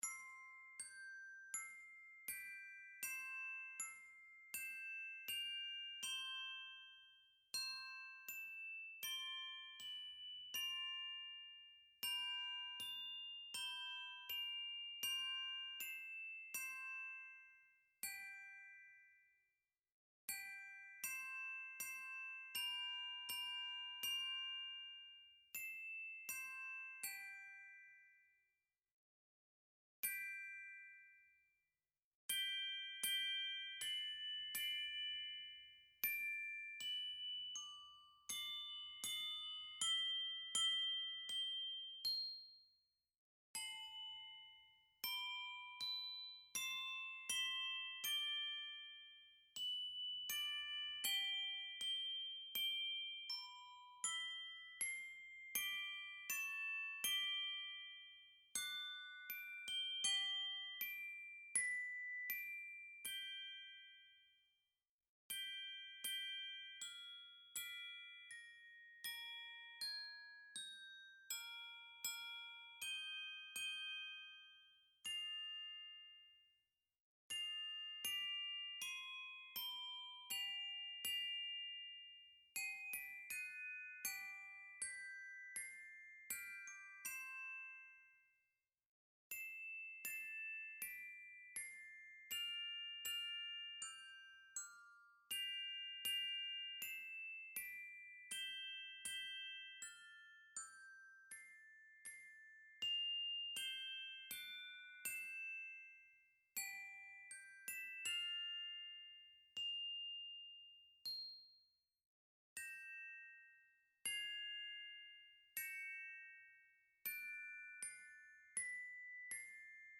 Voicing: Mallet Quartet